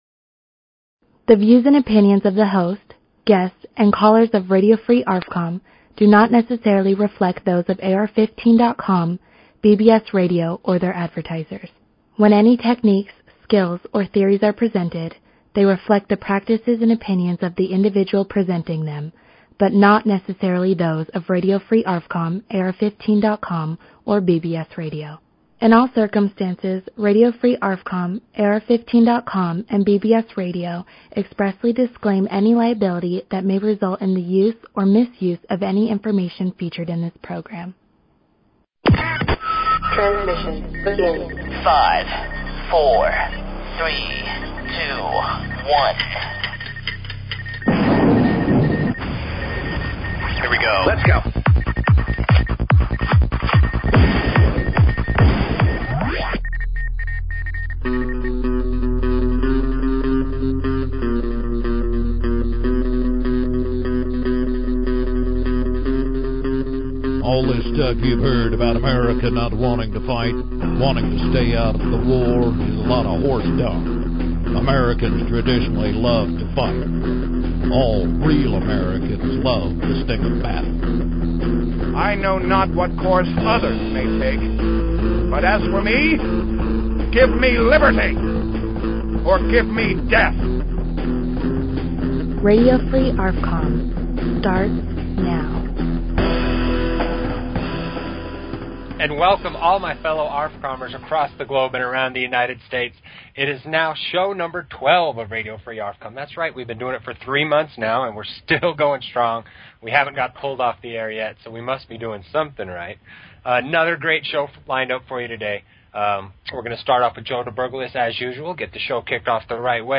Talk Show Episode, Audio Podcast, Radio_Free_ARFCOM and Courtesy of BBS Radio on , show guests , about , categorized as